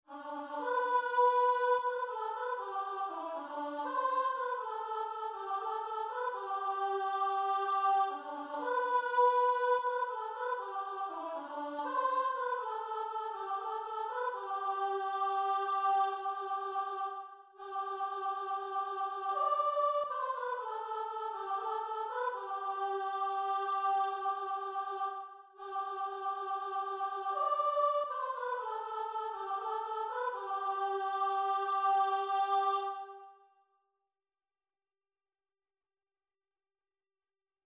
Free Sheet music for Choir (SATB)
4/4 (View more 4/4 Music)
Joyfully = c.120
G major (Sounding Pitch) (View more G major Music for Choir )